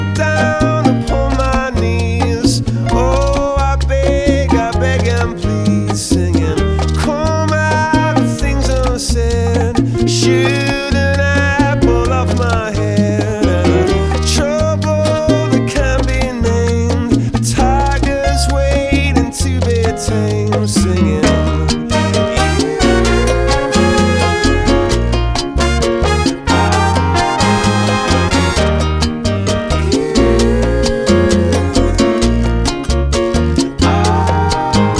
# Música tropical